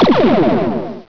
SHLASER.WAV